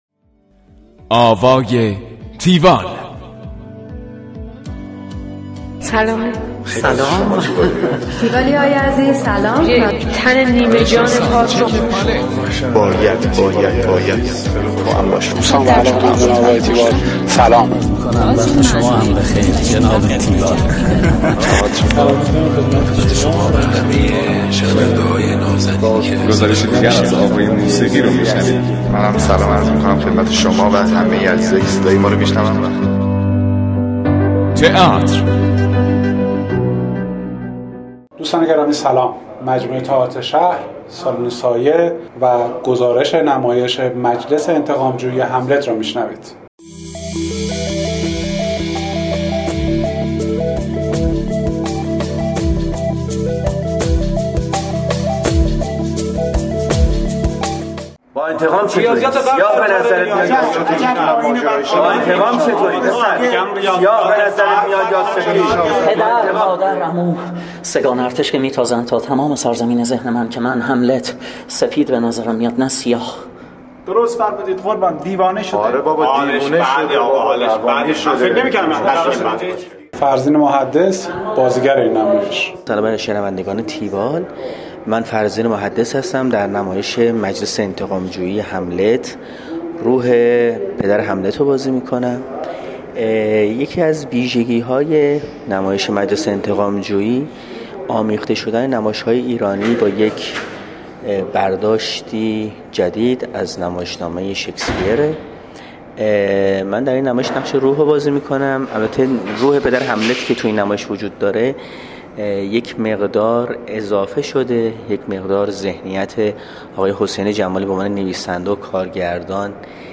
گزارش آوای تیوال از نمایش مجلس انتقام جویی هملت